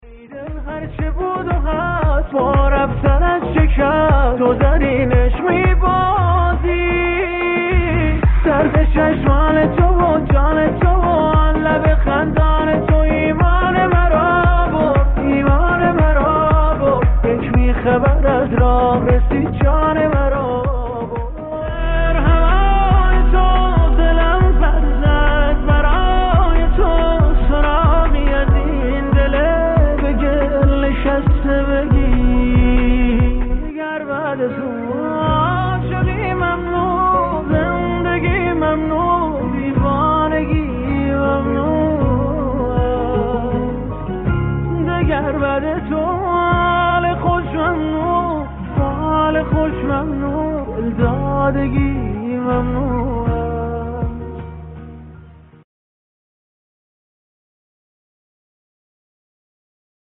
گلچین ریمیکس پشت سر هم آهنگ های